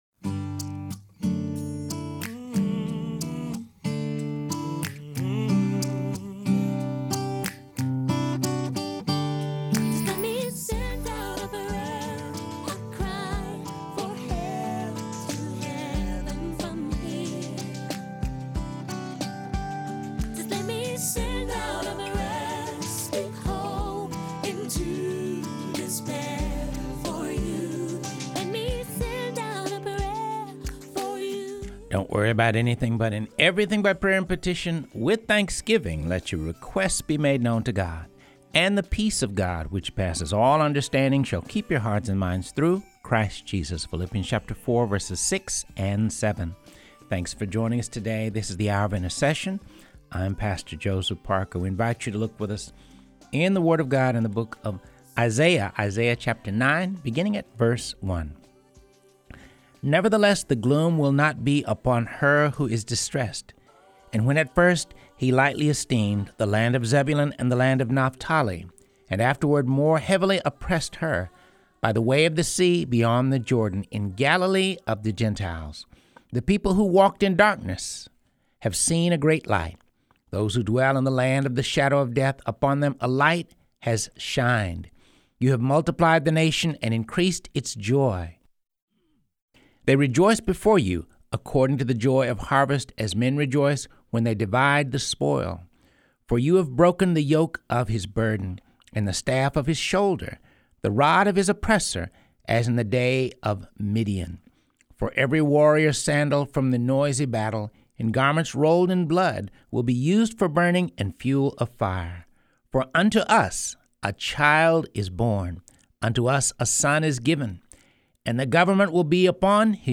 This show covers topics of prayer, intercession, the Word of God, and it features interviews with pastors and religious leaders.